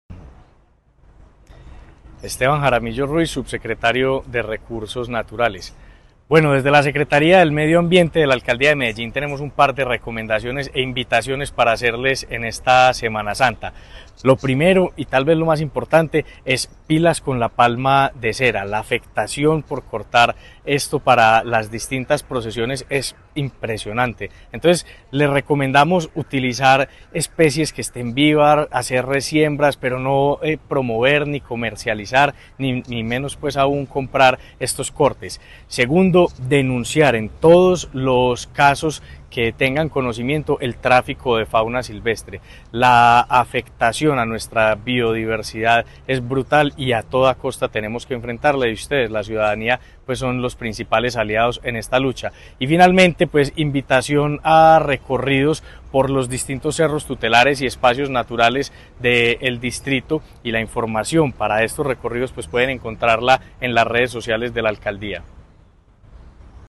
Palabras de subsecretario de Recursos Naturales Renovables, Esteban Jaramillo Ruiz